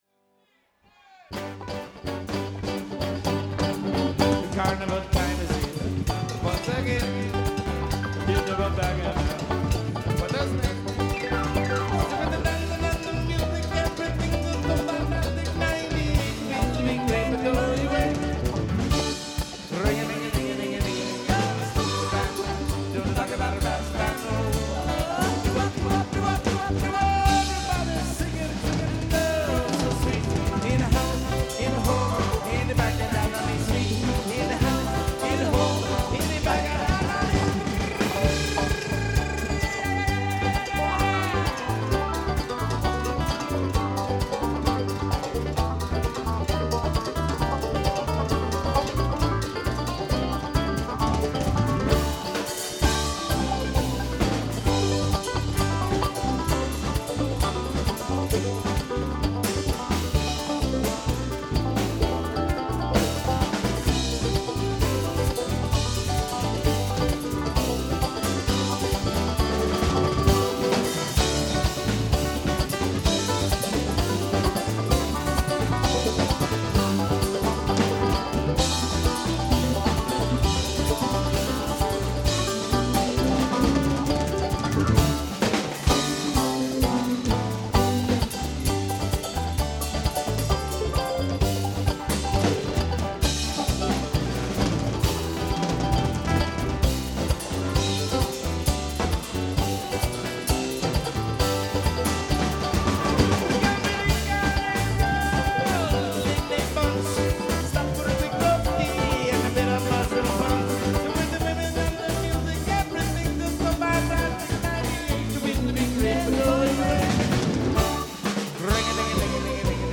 Support Live Music!